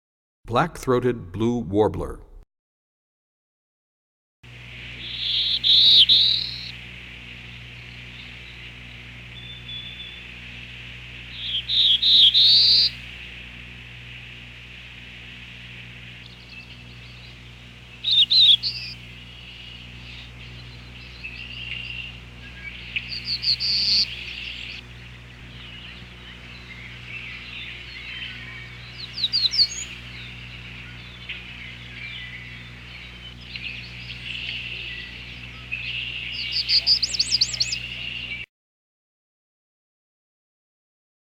13 Black Throated Blue Warbler.mp3